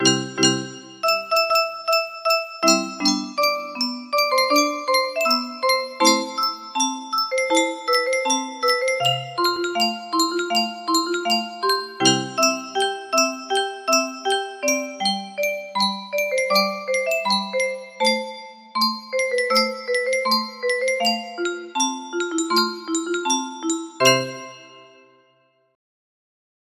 Estribillo